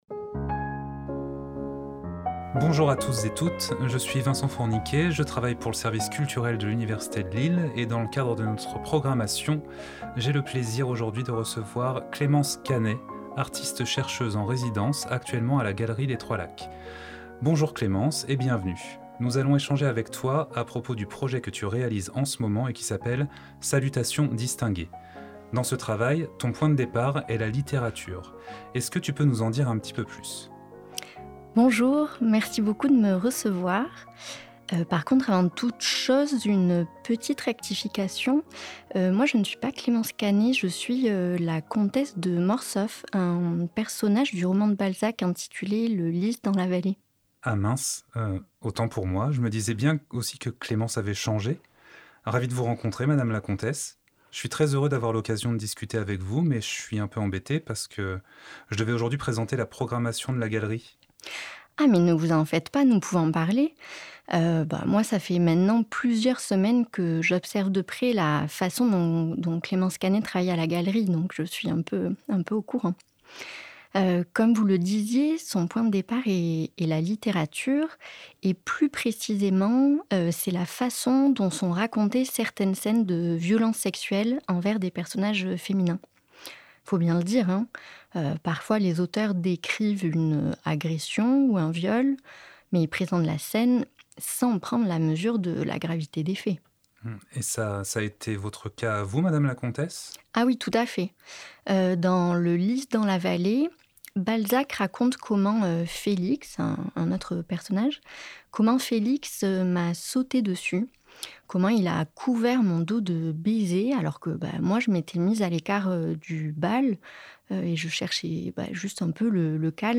entretien-salutations-distinguees.mp3